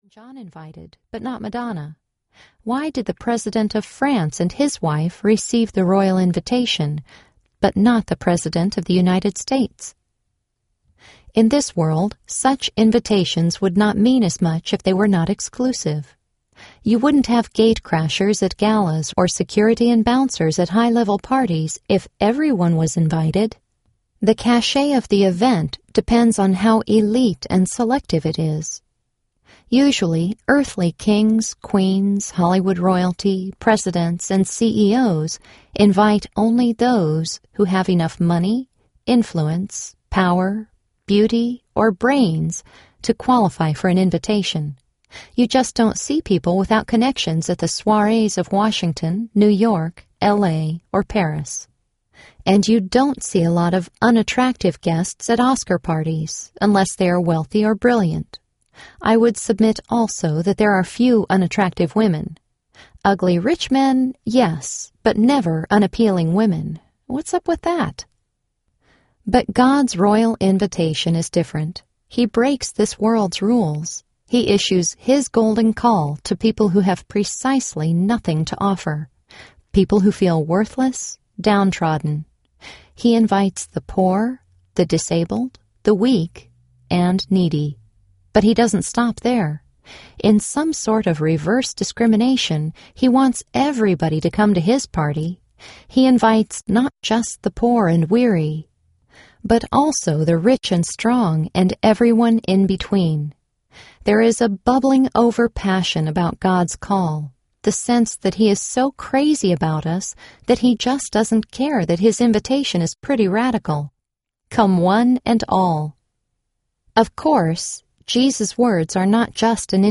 Come, Sit, Stay Audiobook
Narrator
6.9 Hrs. – Unabridged